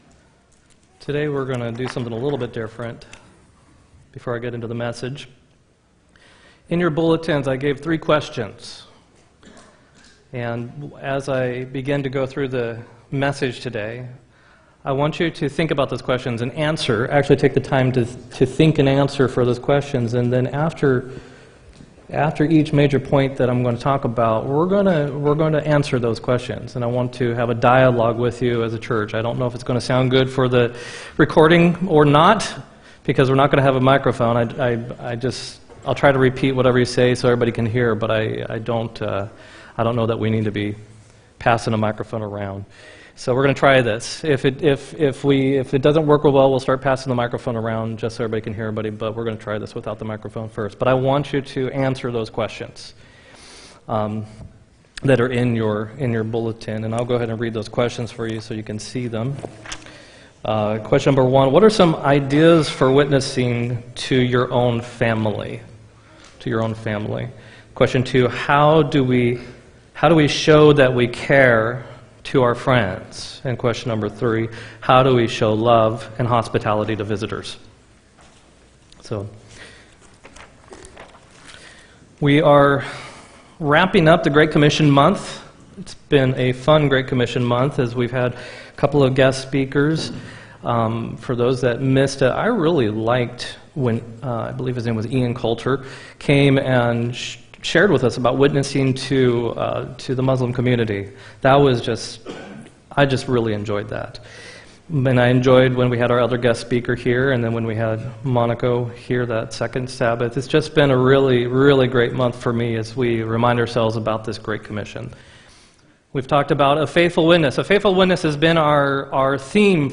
10-27-18 sermon